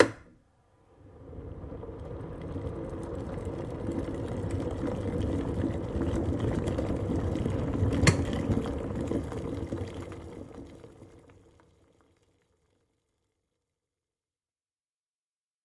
Звуки электрического чайника
На этой странице собраны звуки электрического чайника: от включения до характерного щелчка при завершении кипячения.
Электрический чайник - Альтернативный выбор